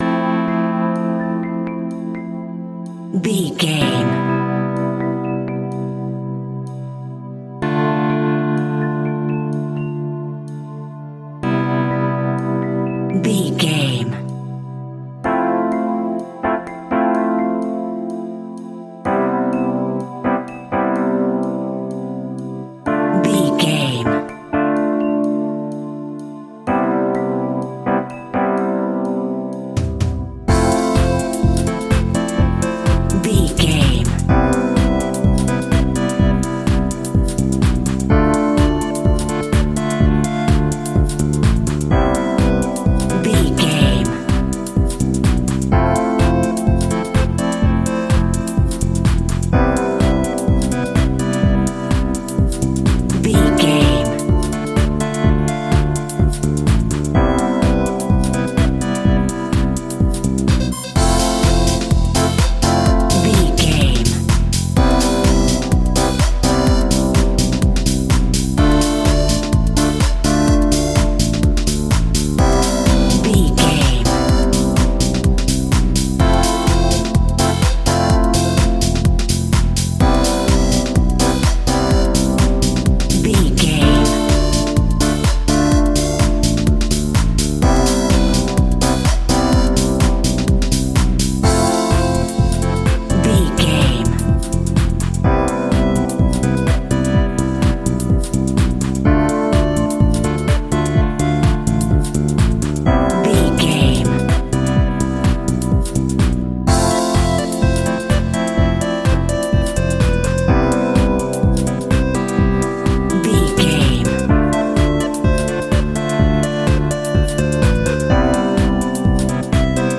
Ionian/Major
energetic
uplifting
hypnotic
groovy
drums
bass guitar
synthesiser
percussion
flute
80s music
synth bass
synth lead